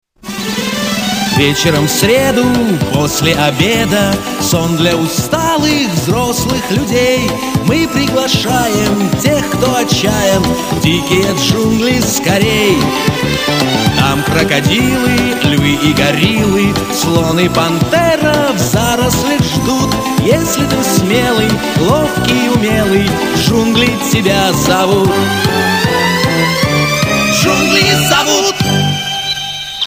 Звук начальной песни